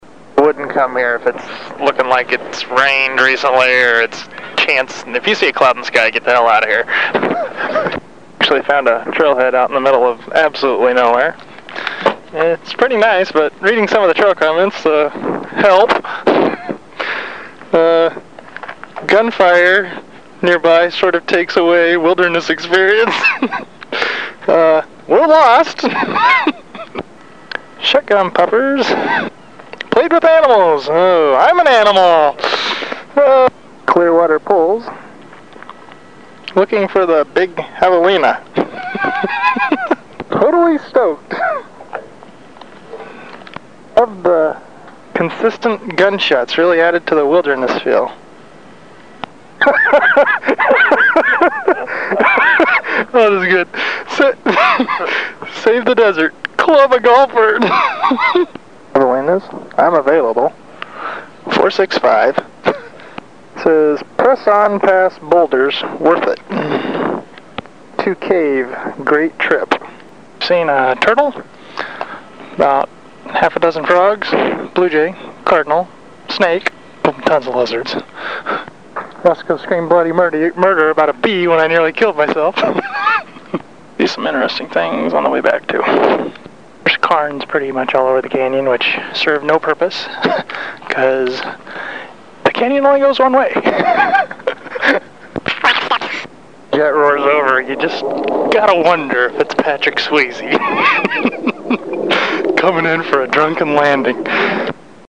reading trail log